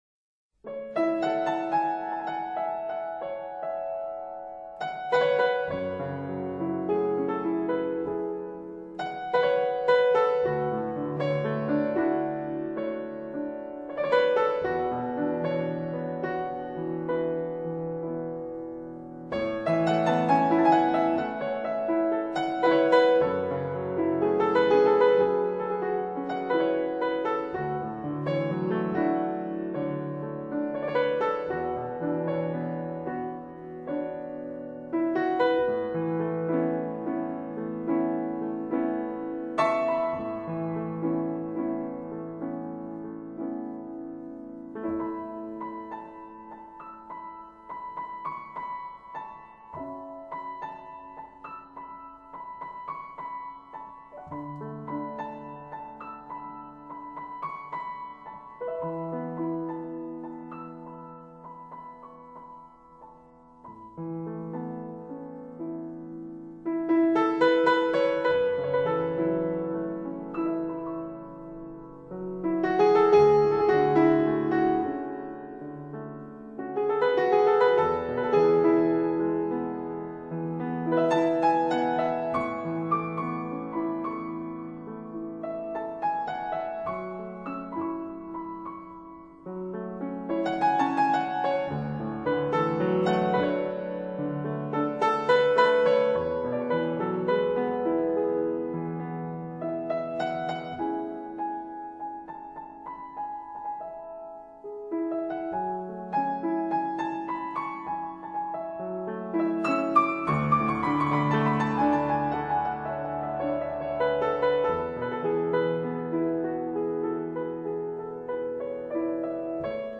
爵士女钢琴家